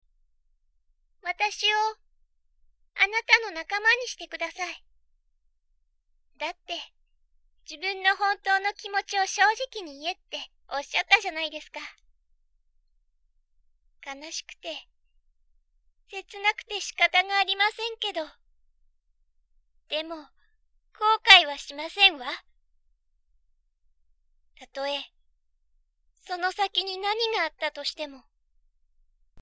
女性：幼年〜老年
男性：幼年〜青年（中性的）
素の声は、高音が優勢な少年っぽい声です。
元気、ダーク、優しげ　などなど
サンプル 青年（中性・悪役風）
少年（ヒーロー風）
女性（お姉さん風）